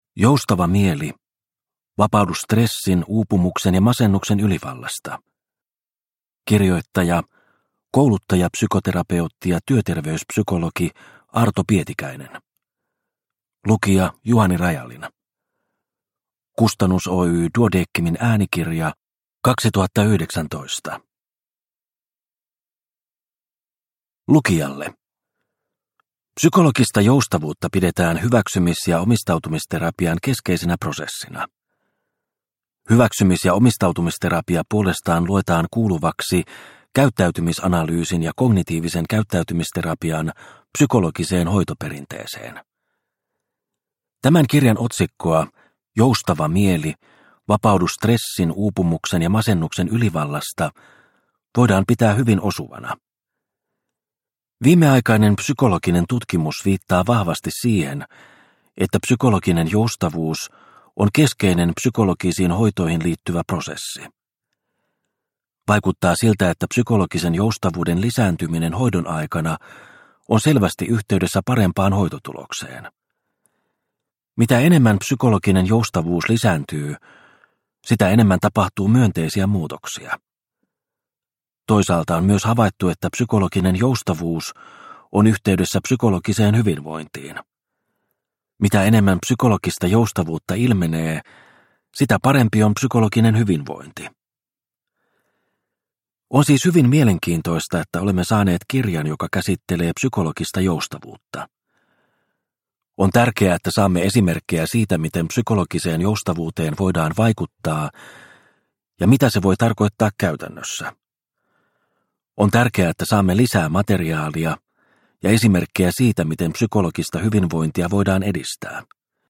Joustava mieli – Ljudbok – Laddas ner